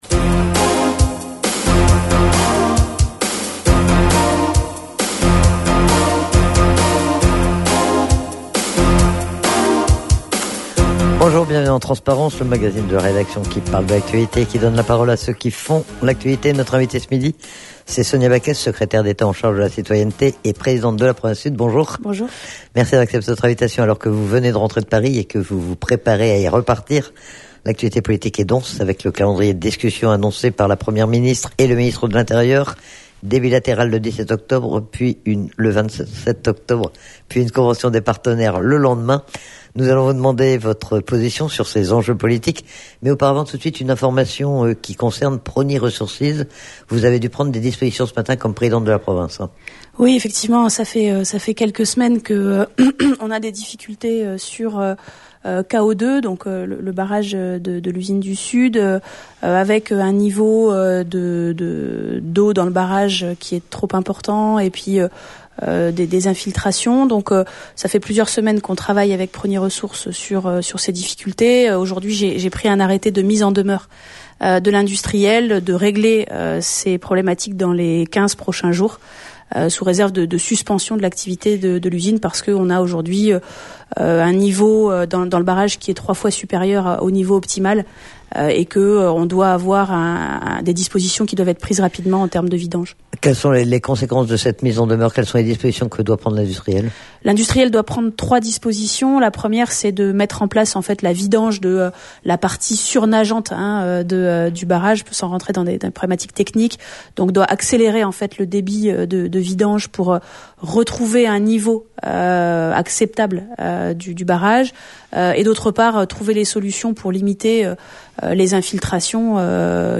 Menu La fréquence aux couleurs de la France En direct Accueil Podcasts TRANSPARENCE : VENDREDI 14/10/22 TRANSPARENCE : VENDREDI 14/10/22 13 octobre 2022 à 15:00 Écouter Télécharger Sonia Backès invitée de Transparence.